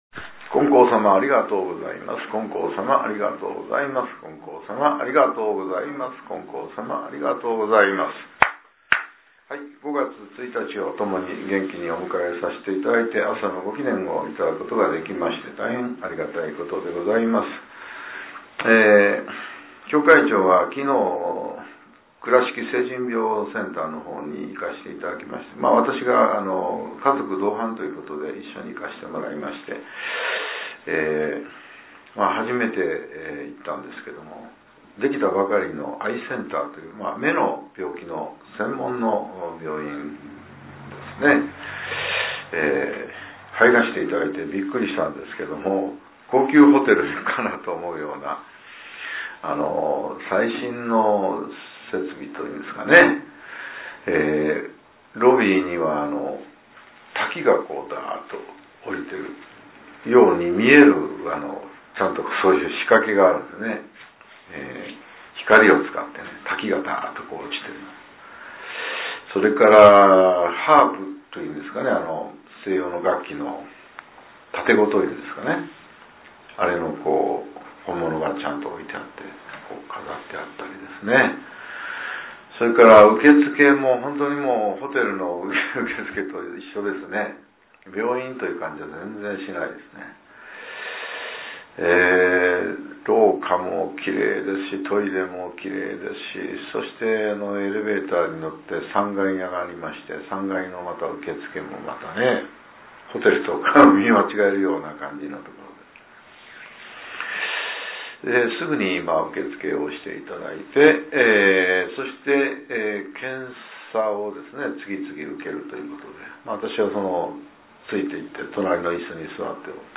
令和７年５月１日（朝）のお話が、音声ブログとして更新されています。